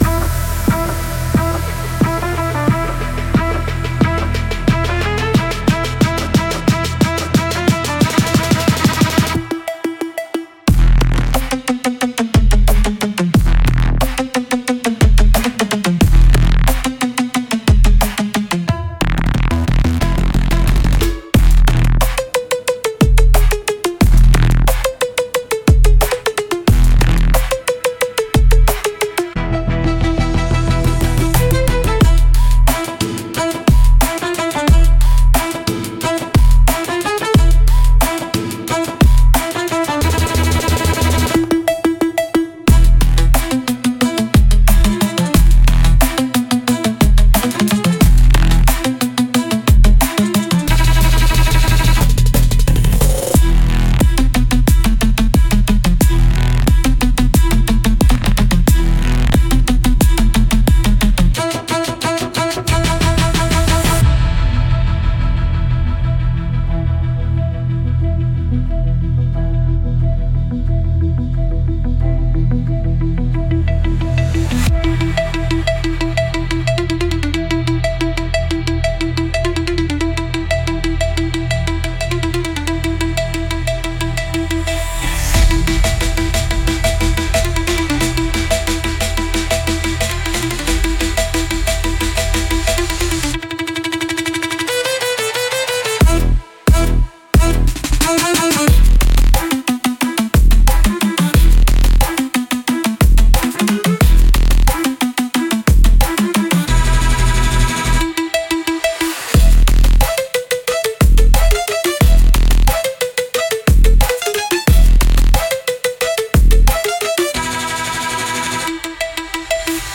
Home Download DANCE/ELECTRO/HOUSE